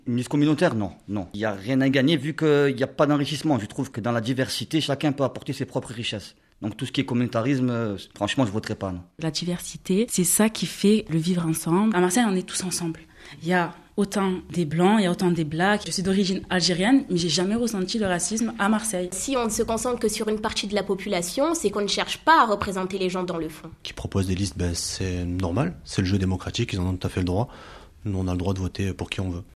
" - Micro-trottoir à Marseille où un ce parti est loin de faire l’unanimité chez les musulmans